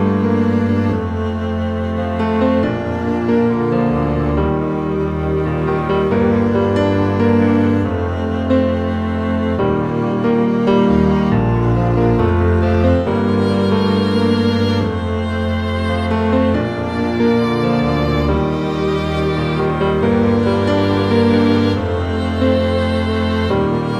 Down 3 Male Key